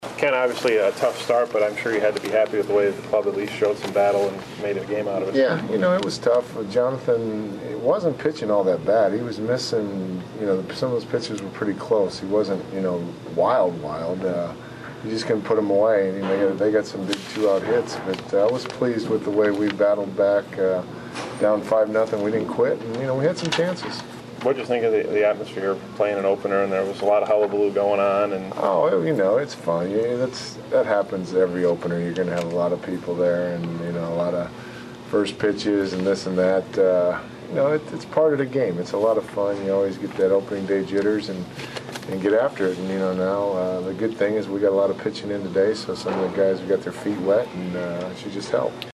post game